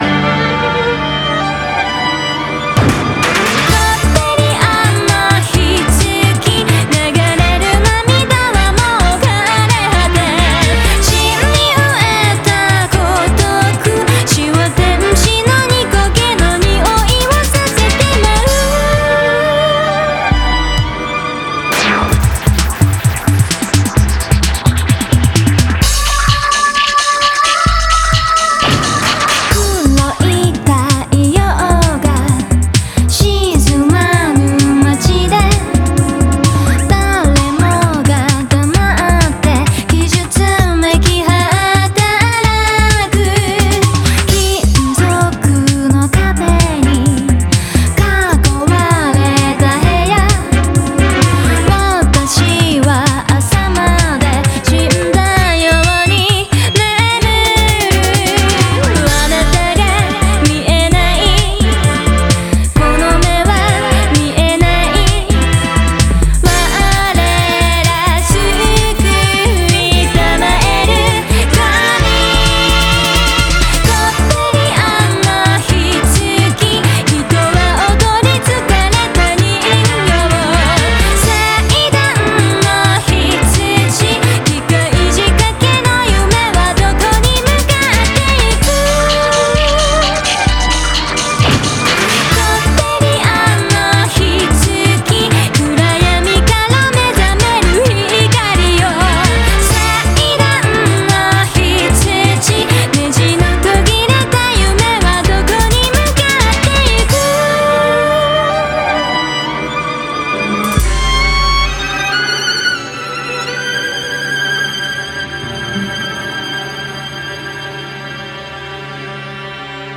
BPM64-129
Audio QualityPerfect (High Quality)